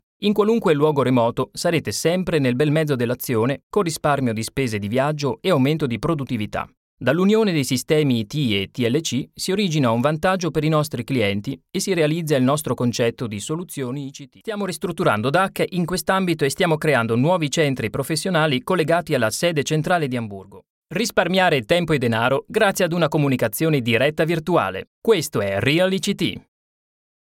smooth, enthusiastic and warm with his Italian mother tongue accent
Sprechprobe: Werbung (Muttersprache):
Italien voice over artist, his vocal range is perfectly suited for corporate narration, IVR and commercials in several other languages, including English, German and Spanish.